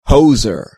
• : -əʊzə(r)